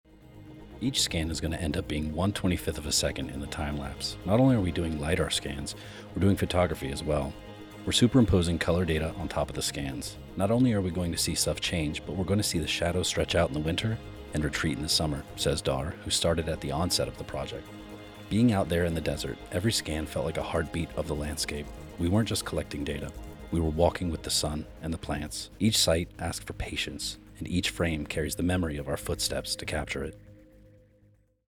Hear from one of the scanners